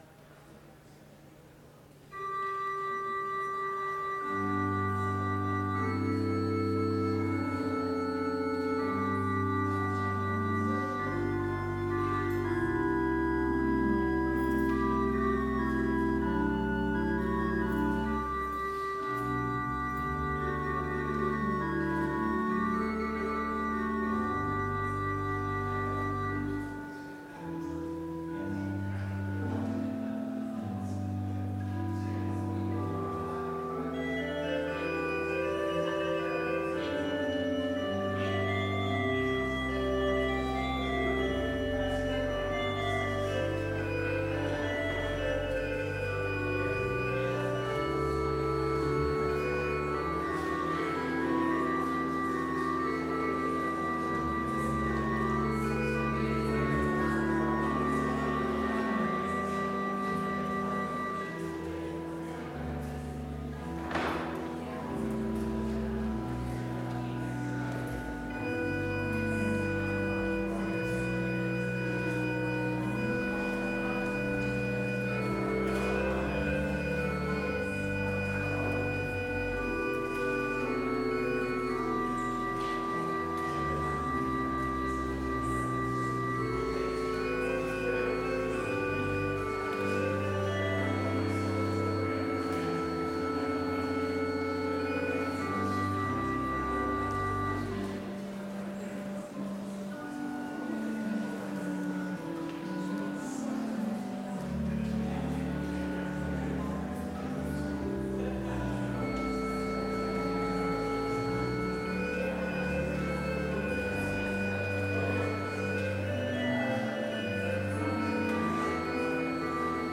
Complete service audio for Chapel - January 30, 2020